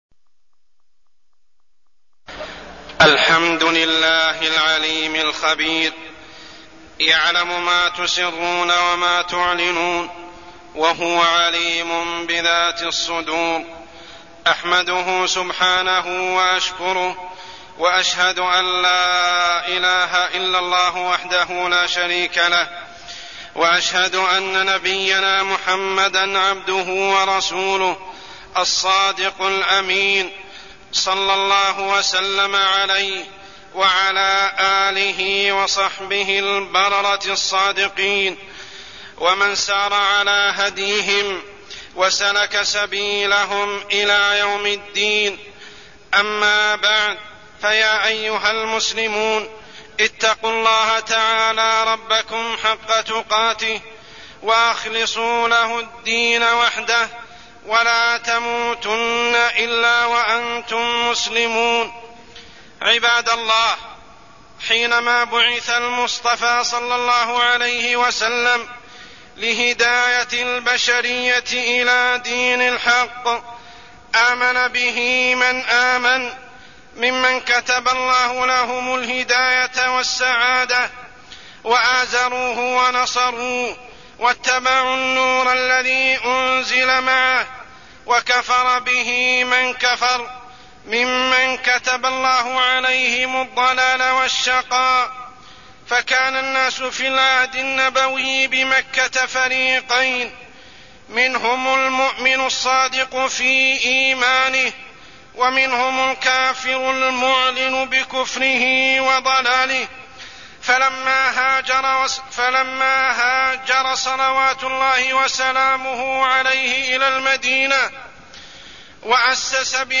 تاريخ النشر ٣٠ شوال ١٤١٥ هـ المكان: المسجد الحرام الشيخ: عمر السبيل عمر السبيل المنافقون